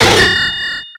Cri de Dinoclier dans Pokémon X et Y.